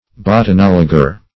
Botanologer \Bot`a*nol"o*ger\, n.